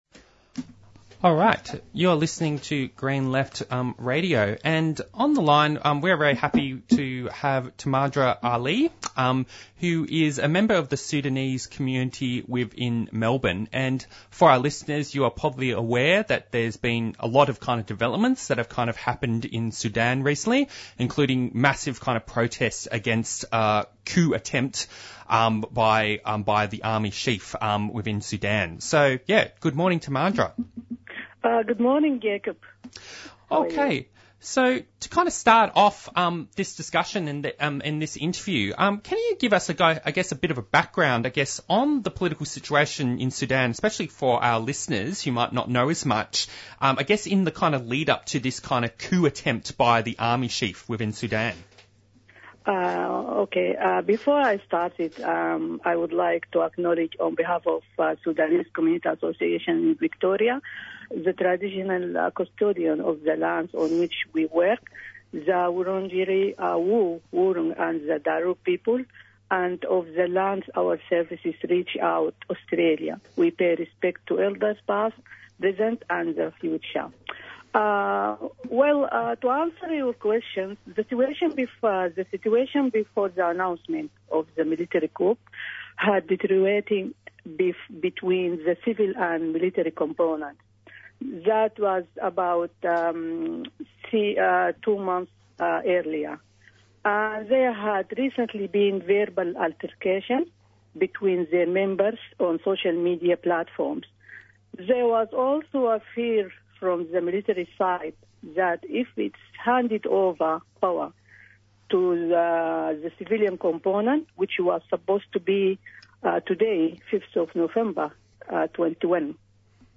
Discussion of the following newsreports from the presenters
Interviews and Discussion